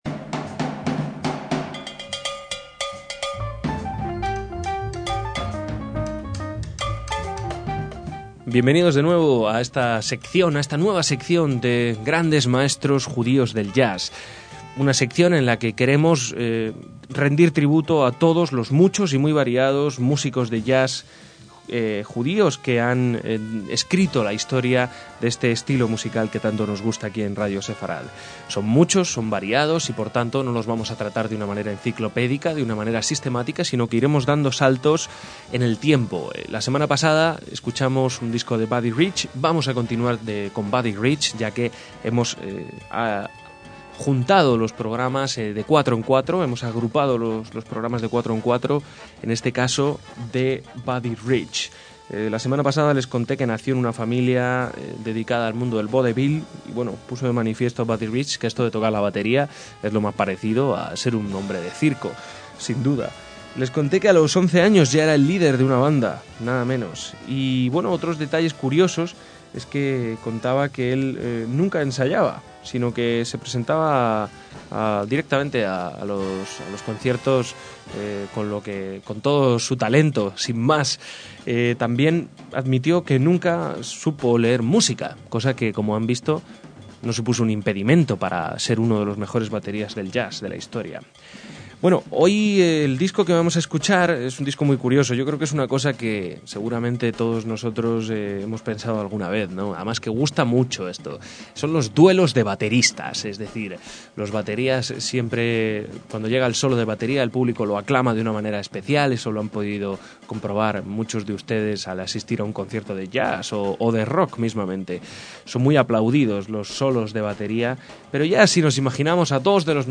bateristas de jazz
cada uno en su canal o lado del sonido estéreo.
saxo alto
trombón
contrabajo
piano
saxo tenor
trompeta